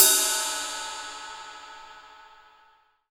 Index of /90_sSampleCDs/AKAI S6000 CD-ROM - Volume 3/Ride_Cymbal1/18INCH_ZIL_RIDE